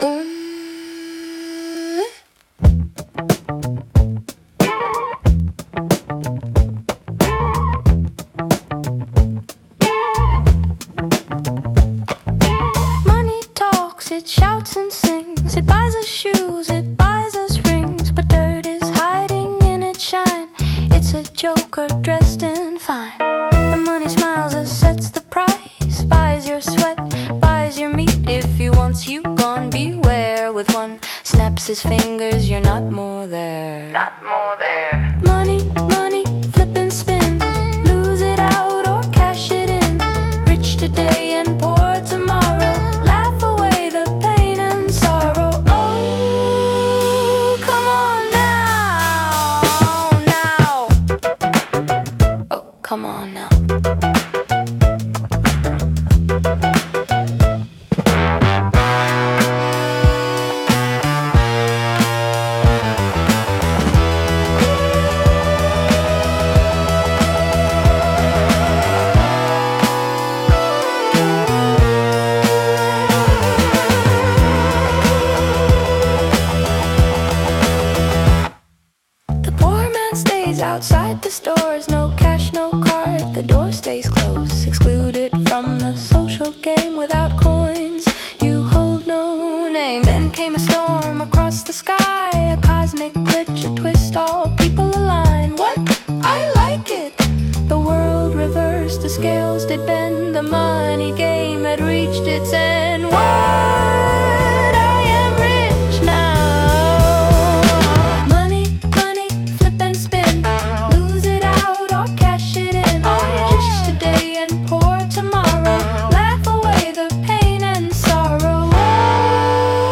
“Money Goes Round and Round” is an upbeat satire about the absurd power of money in modern society.
With a cheerful rhythm and carnival-style chorus, full of nonsense sounds like “bling blang boom”, the song turns a serious topic into a playful social commentary.
Musically, the song has a circus-like energy, with catchy rhymes, quick rhythm, and a comic theatrical flair.